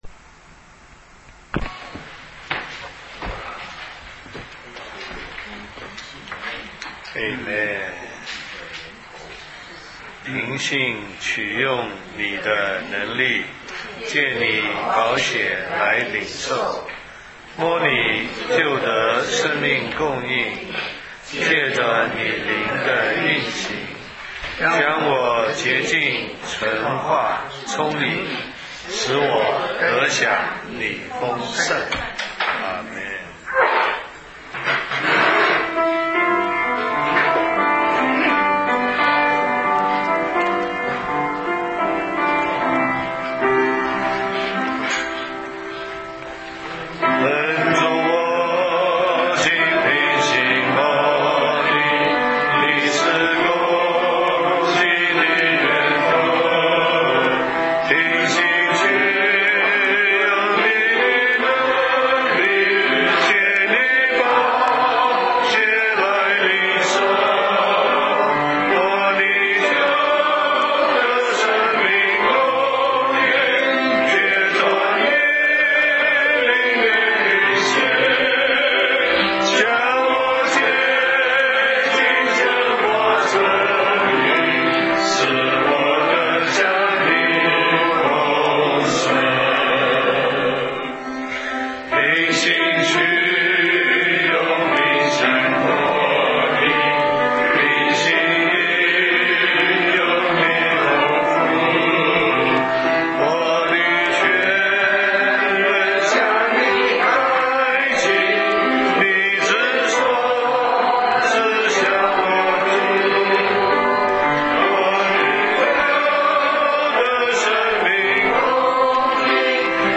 Prayer Meetings